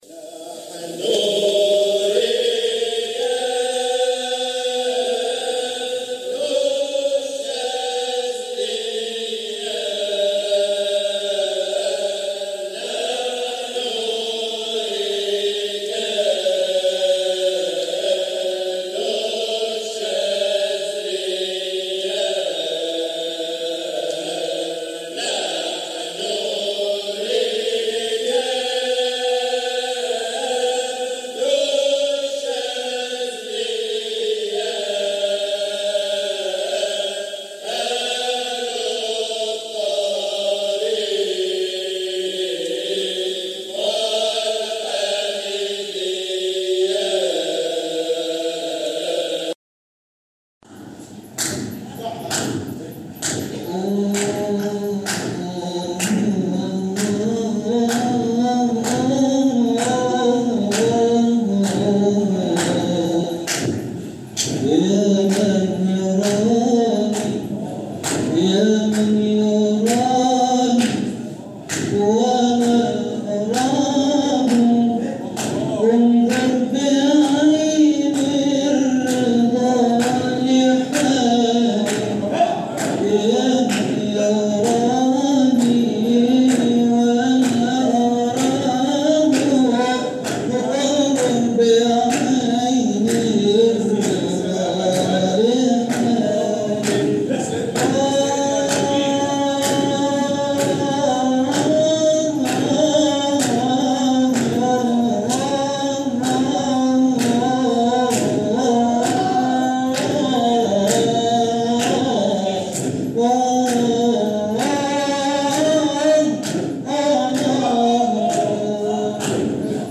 جزء من حلقة ذكر بمسجد مولانا المؤسس قُدس سره